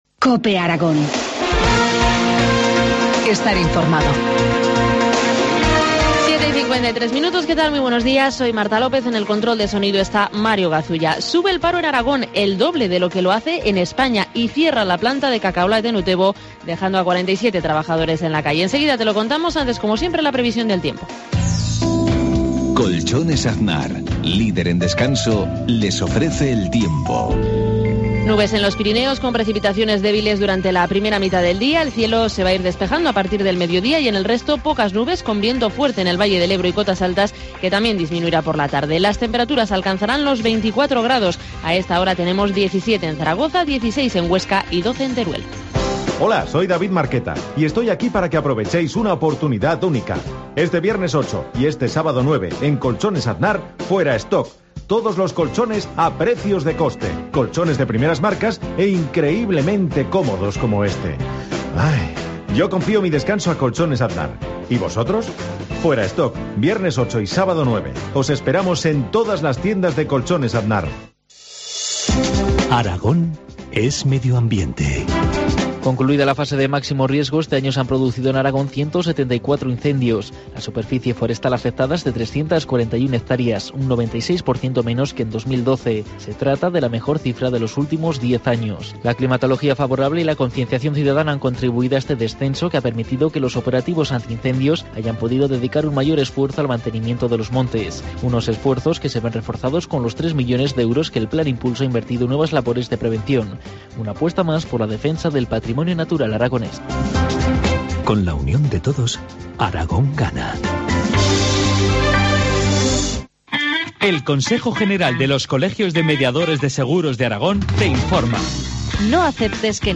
Informativo matinal, miércoles 6 de noviembre, 7.53 horas